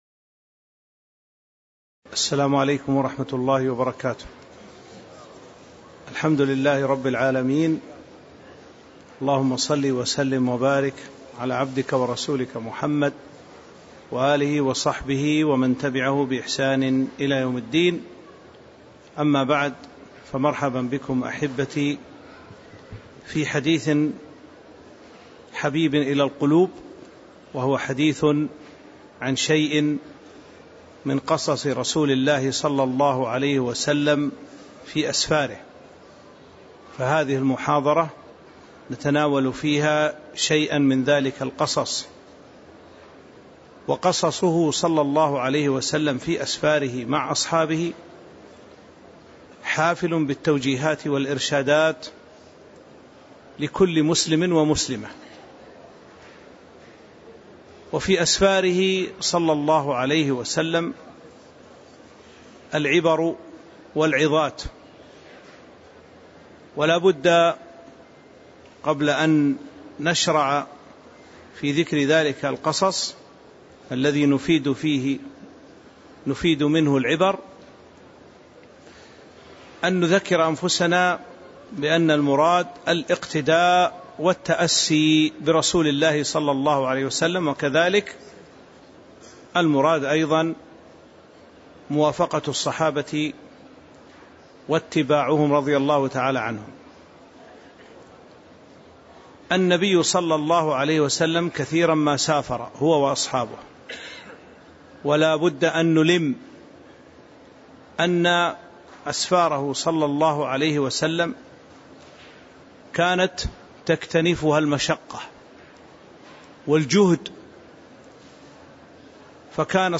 تاريخ النشر ١٨ رجب ١٤٤٥ هـ المكان: المسجد النبوي الشيخ